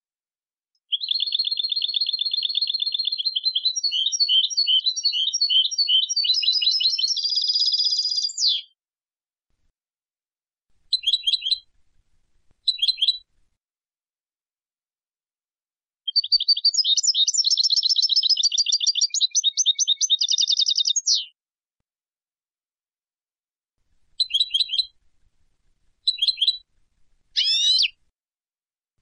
Algerian Goldfinch-sound-HIingtone
algerian-goldfinch.mp3